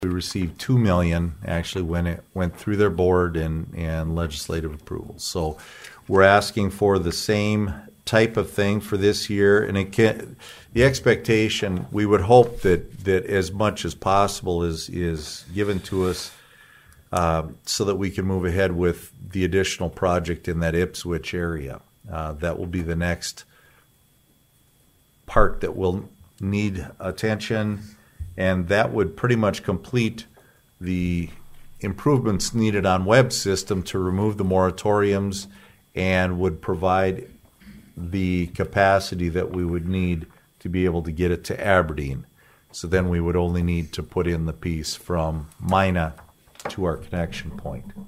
ABERDEEN, S.D.(HubCityRadio)- The Aberdeen City Manager, Robin Bobzien updated on the water projects during Monday’s City Council meeting.